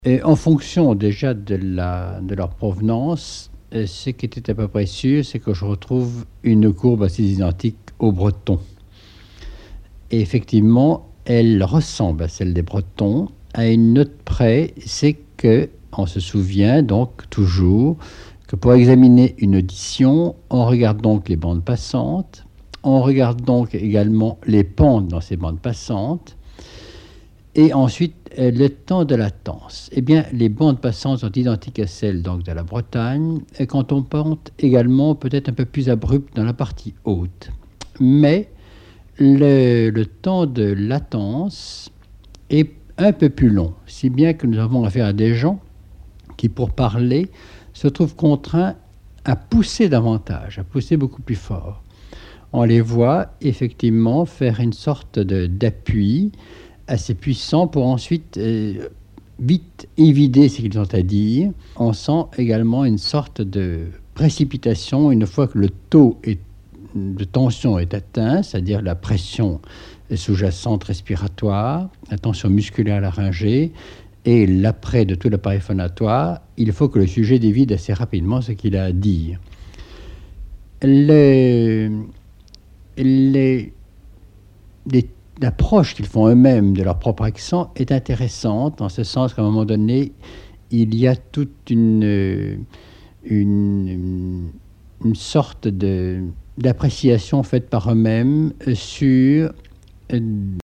Emisson Départementale, sur Radio France Culture
Catégorie Témoignage